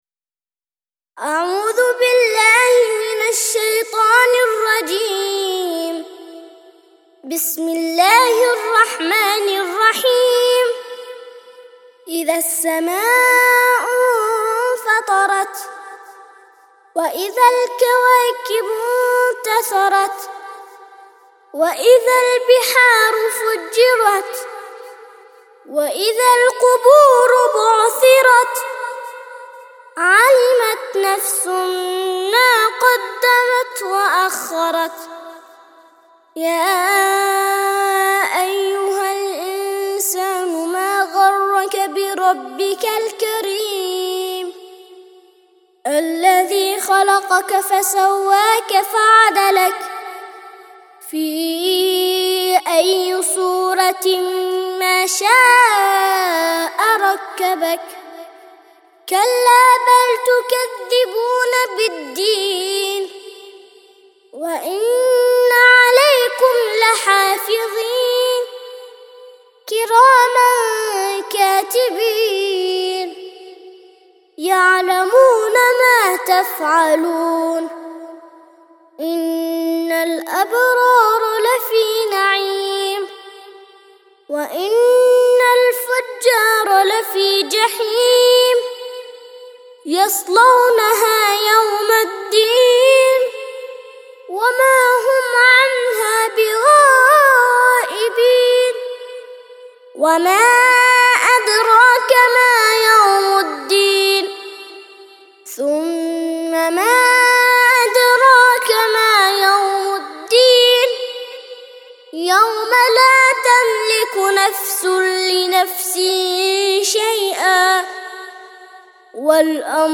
82- سورة الإنفطار - ترتيل سورة الإنفطار للأطفال لحفظ الملف في مجلد خاص اضغط بالزر الأيمن هنا ثم اختر (حفظ الهدف باسم - Save Target As) واختر المكان المناسب